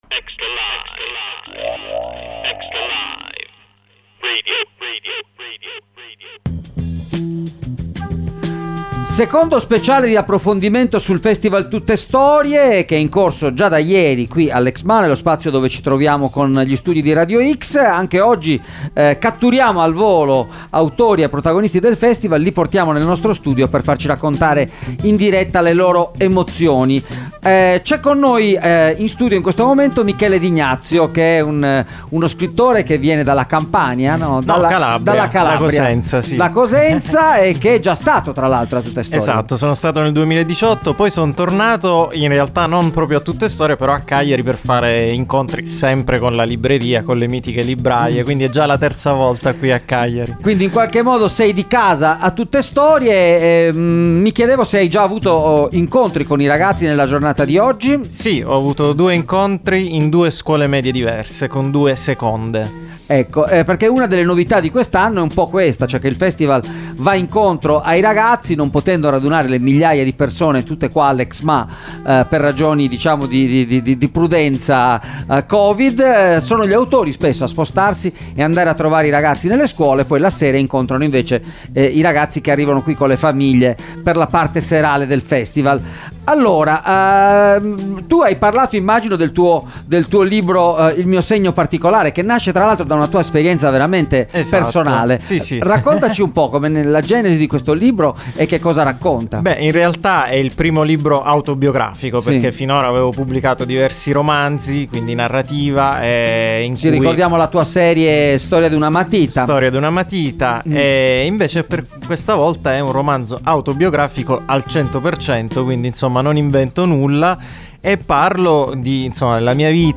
Tuttestorie 2021, in diretta dal festival